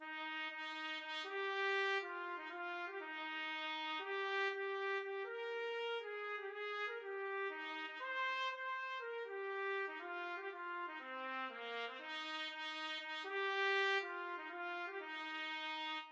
Moderately Fast
4/4 (View more 4/4 Music)
Eb major (Sounding Pitch) F major (Trumpet in Bb) (View more Eb major Music for Trumpet )
Trumpet  (View more Easy Trumpet Music)
Traditional (View more Traditional Trumpet Music)
ten_green_bottlesTPT.mp3